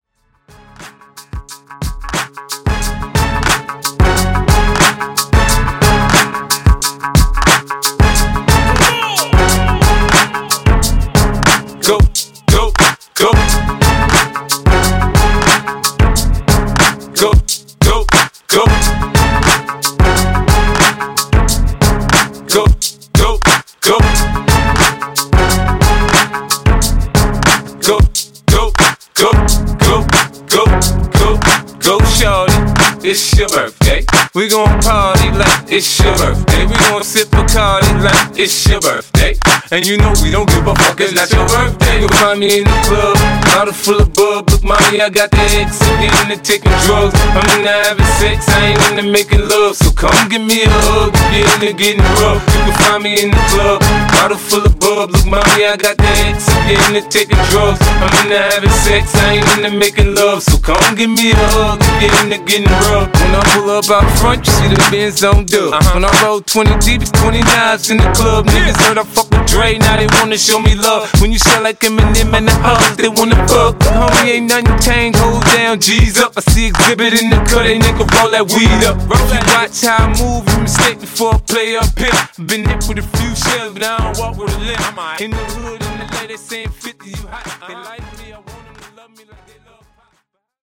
Genre: R & B Version: Clean BPM: 120 Time